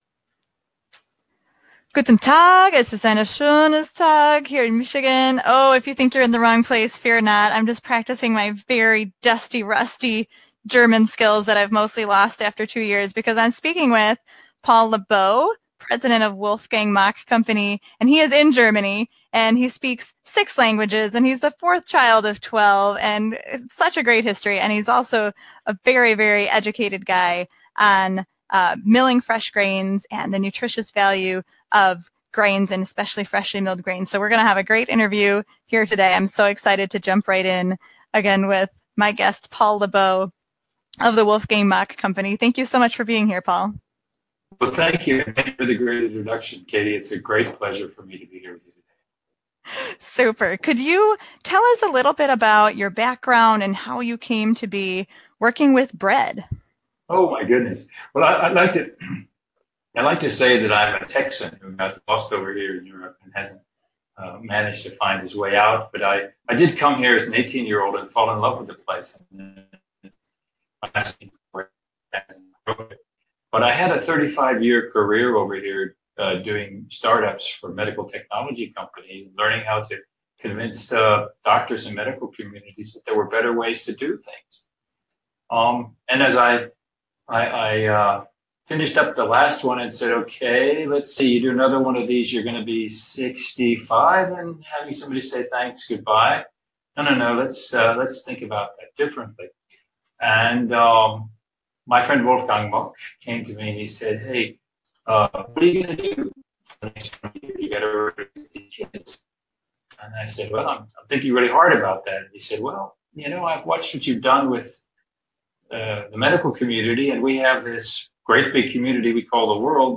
He talks to me all the way from Germany, which does mean that we had some technical issues and decided to turn off video so that audio would be more consistent.